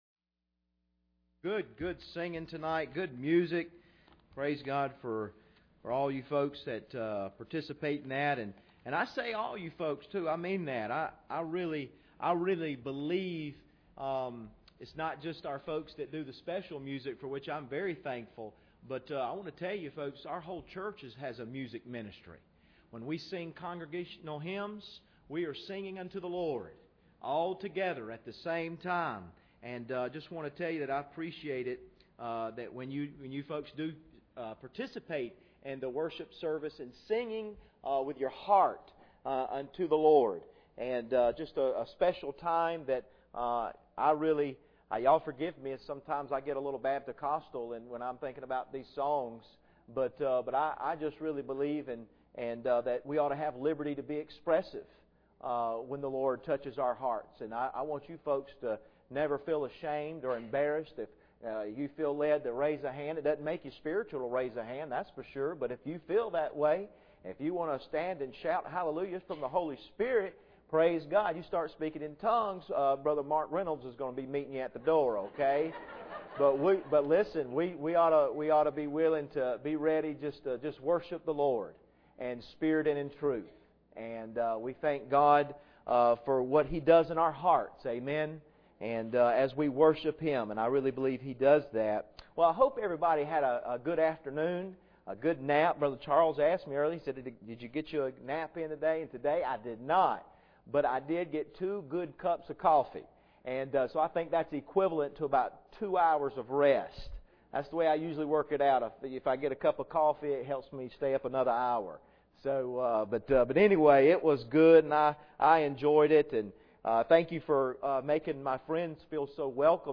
Passage: Daniel 4:10-16 Service Type: Sunday Evening